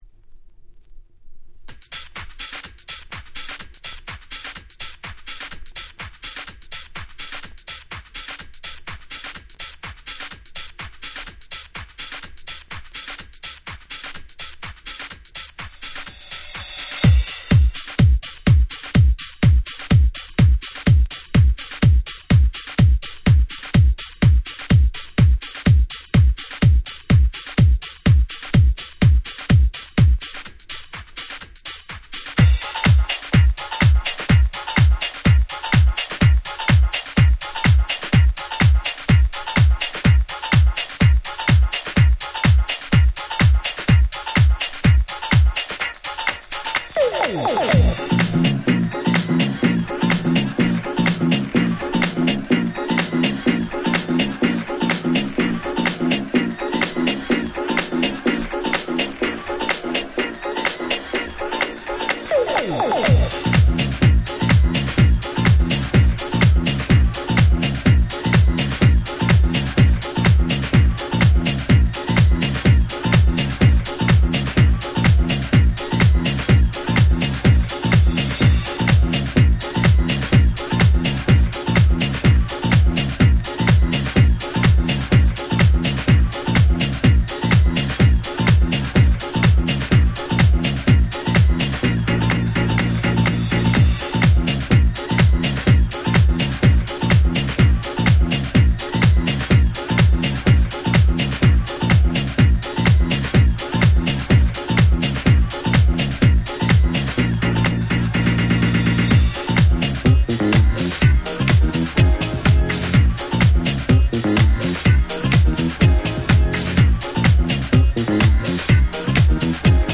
00' オシャレVocal House♪
灰汁の少ないシンプルなオシャレVocal Houseに仕上がっており、自分は当時迷わず即買いな1枚でした♪